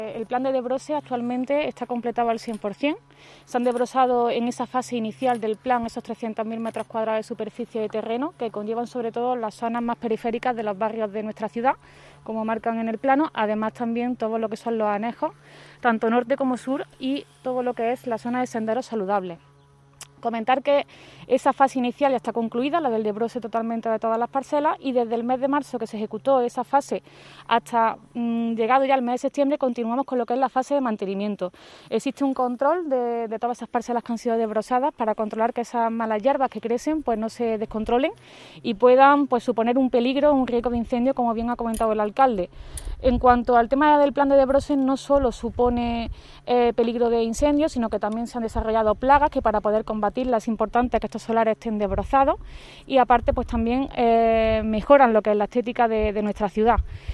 El alcalde de Antequera, Manolo Barón, y la teniente de alcalde delegada de Mantenimiento, Teresa Molina, han informado hoy en rueda de prensa sobre el desarrollo del Plan Municipal de Desbroce 2021 que ya se encuentra en fase de mantenimiento tras su acometida desde el pasado mes de marzo.
Cortes de voz